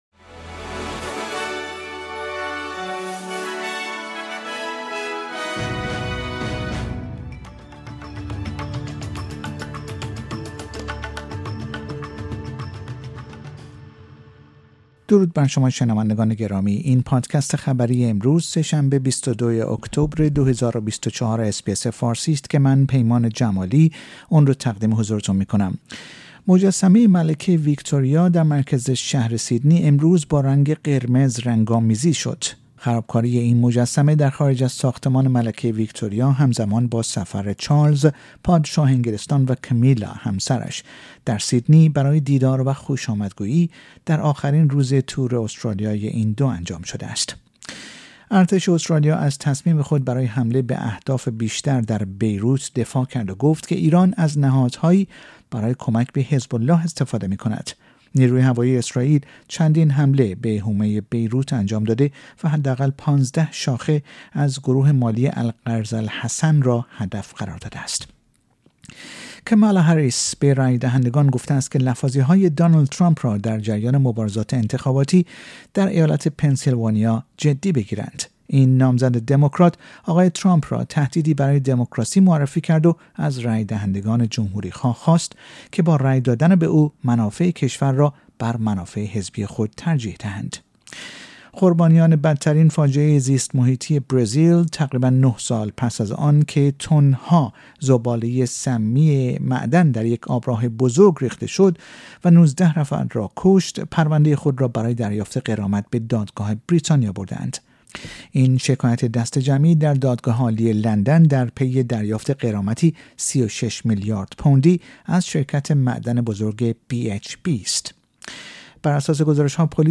در این پادکست خبری مهمترین اخبار استرالیا در روز سه شنبه ۲۲ اکتبر ۲۰۲۴ ارائه شده است.